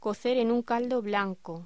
Locución: Cocer en un caldo blanco
voz